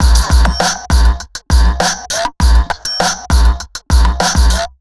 Рок звуки скачать, слушать онлайн ✔в хорошем качестве